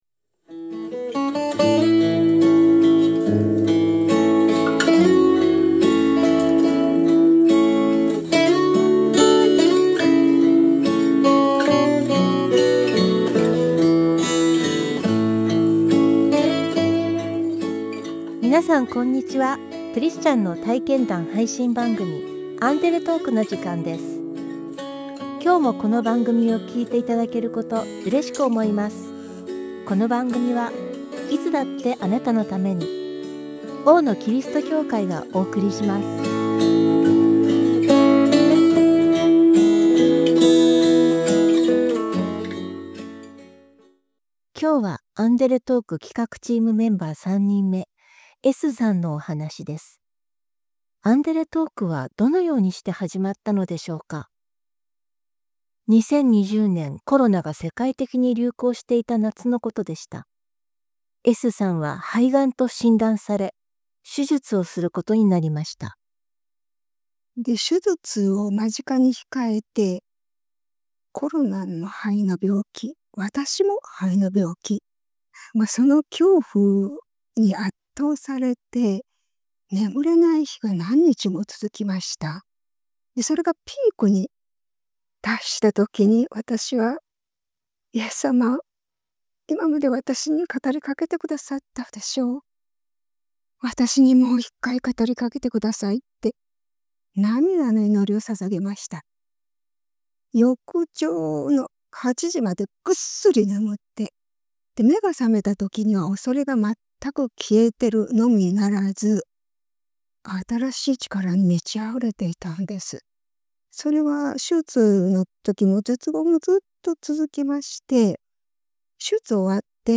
アンデレトークは、そんなあなたにクリスチャンの素顔を紹介する番組です。 インタビューに応えているのは大野キリスト教会のメンバーで、生き生きと自分らしい生き方をしています。